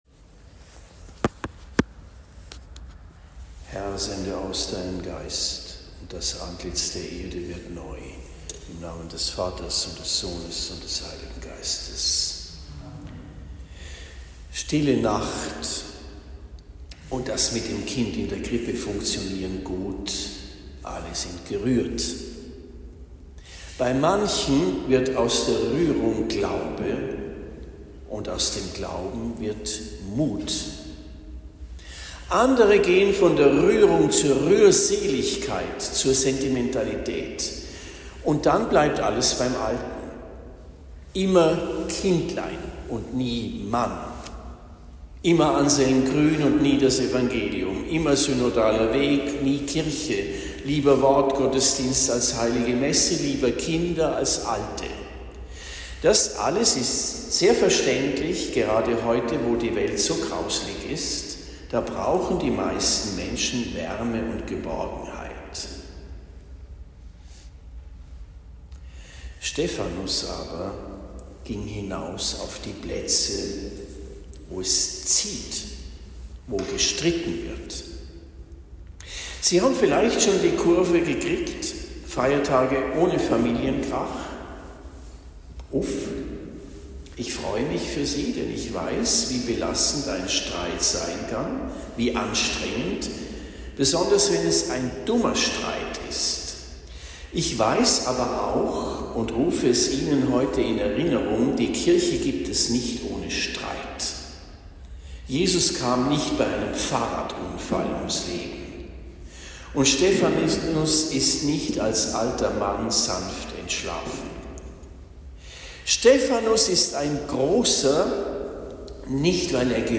Predigt in Waldzell am 26. Dezember 2025